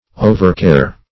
Overcare \O"ver*care`\, n. Excessive care.